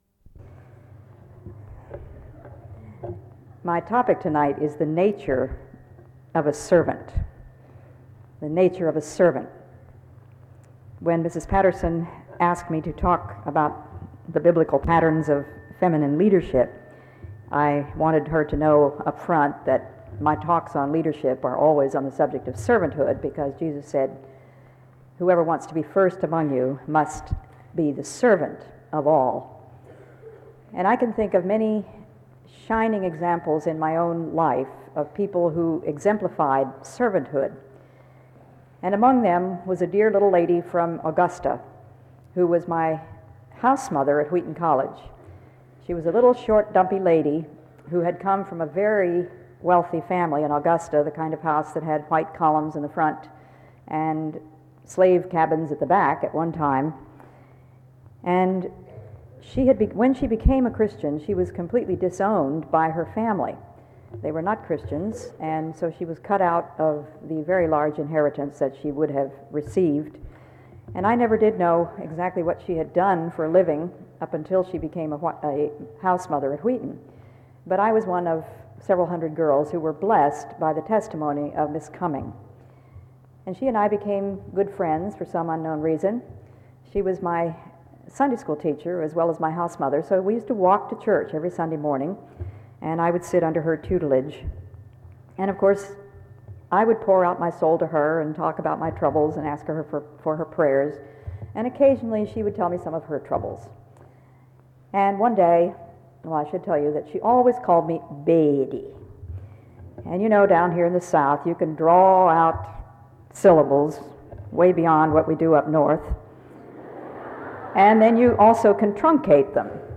File Set | SEBTS_Chapel_Elisabeth_Elliot_1996-02-22_PM.wav | ID: c495410b-ed84-4672-b63b-276ae00f65ab | Hyrax